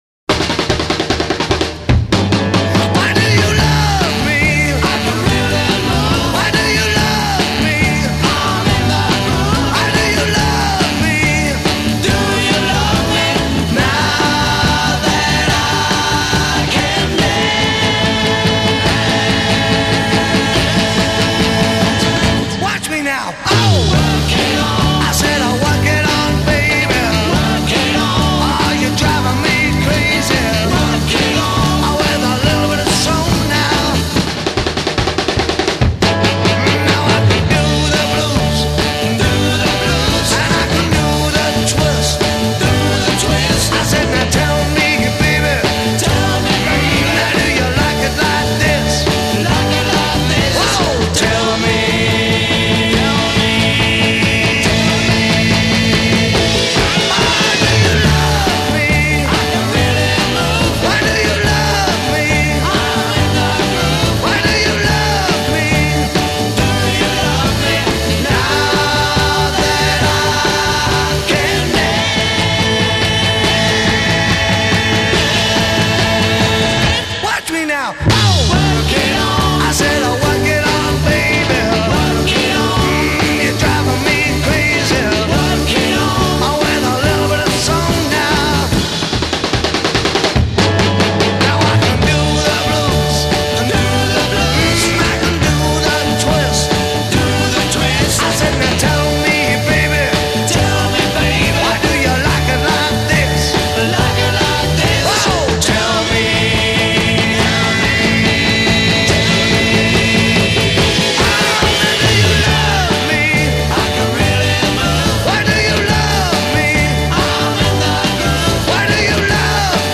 organ and vocals
tenor saxophone
Intro 0:00 4 drum roll
with choral response; stack chord at end
A refrain : 16+8 repeat and fade a